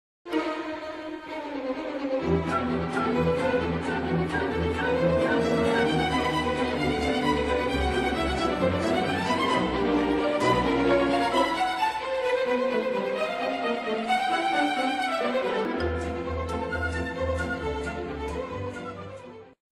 Suite in 4 Sätzen für Salonorchester oder Großes Orchester
Besetzung: variabel – Großes Orchester, Salonorchester